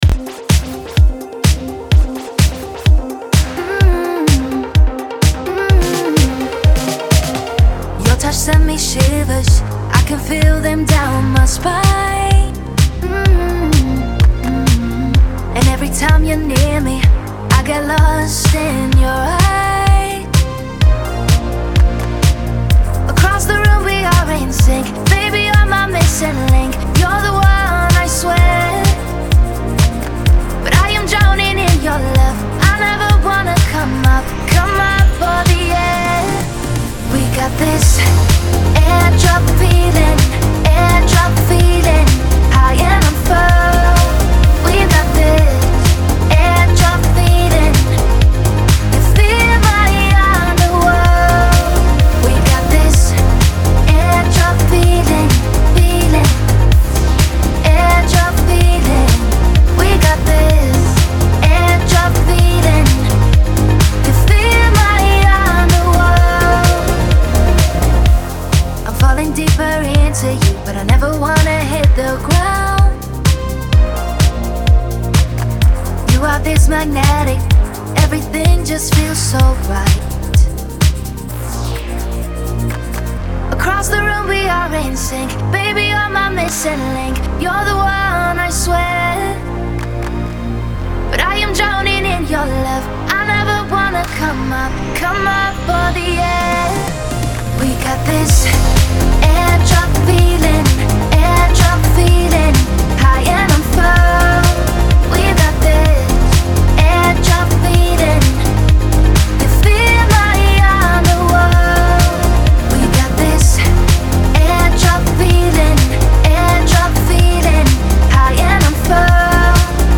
• Жанр: Dance, Electronic